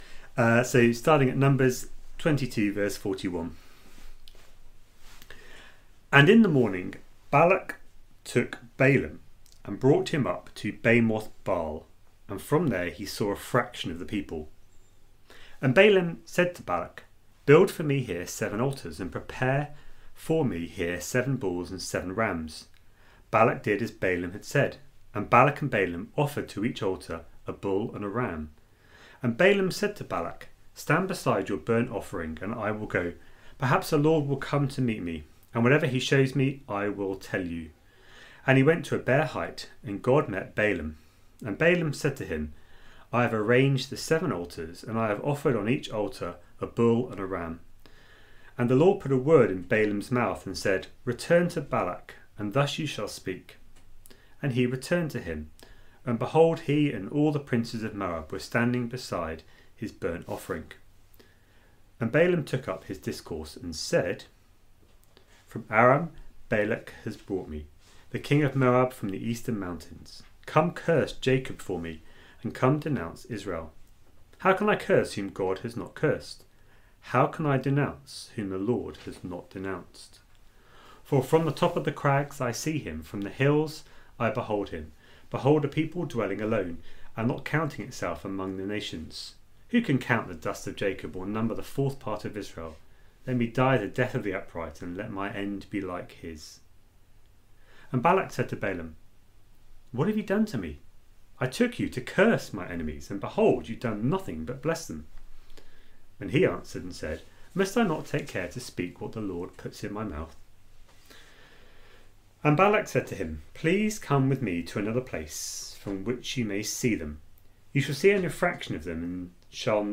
A sermon preached on 17th January, 2021, as part of our Numbers series.